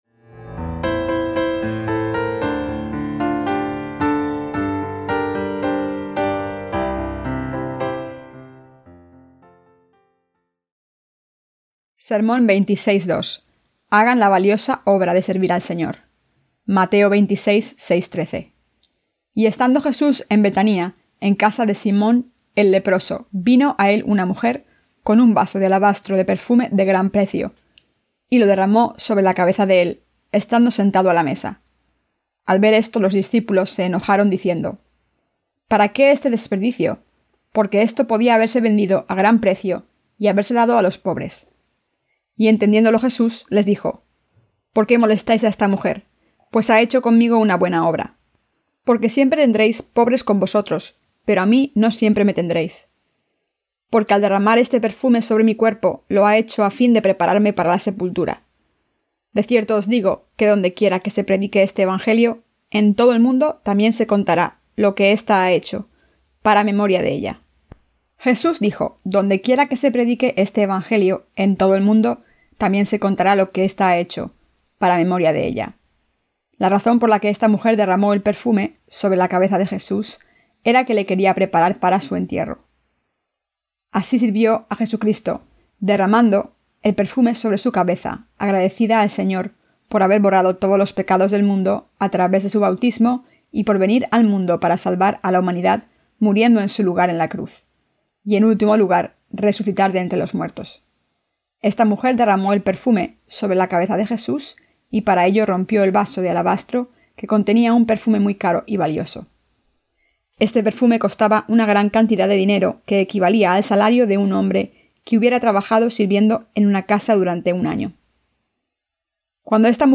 SERMONES SOBRE EL EVANGELIO DE MATEO (VI)-¿A QUIÉN SE LE PRESENTA LA MEJOR VIDA?